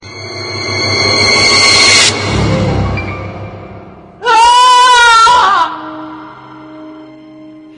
恐怖气氛男人惊叫音效免费音频素材下载